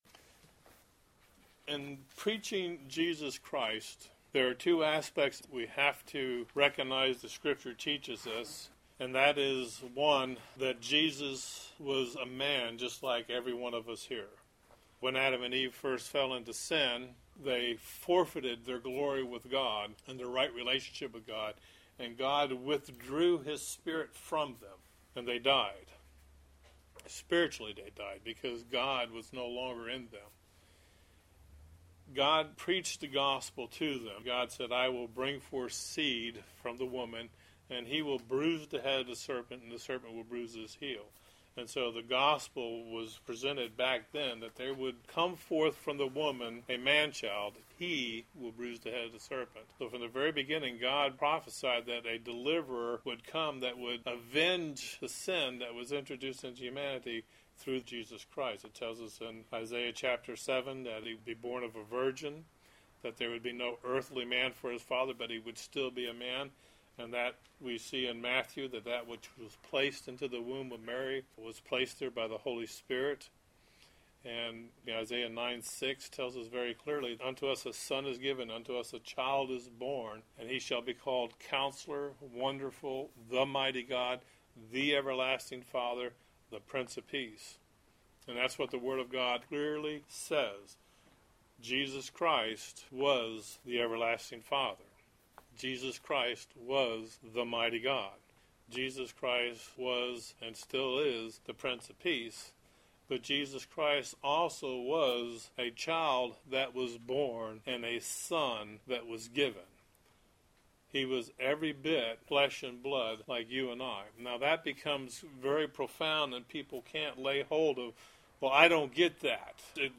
Friday night’s teaching at the Sanctuary – 02-15, 2013